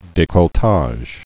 (dākôl-täzh)